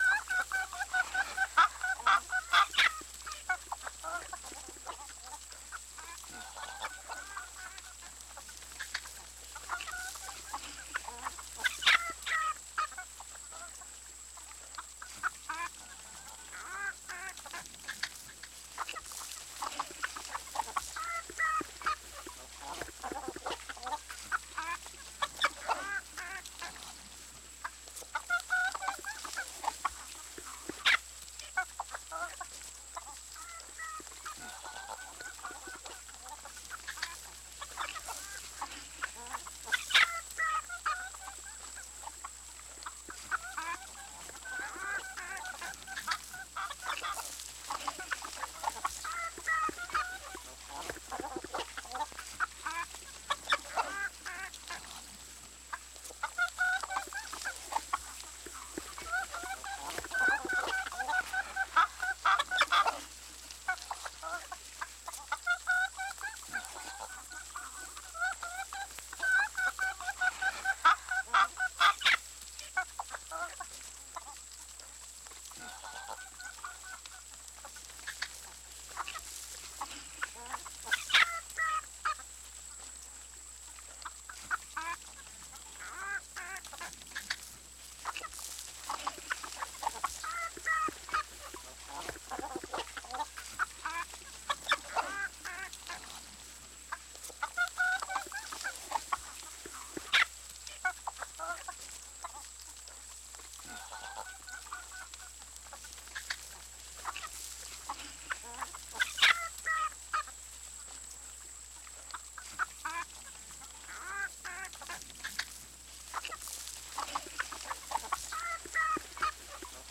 the-sound-of-farm-animals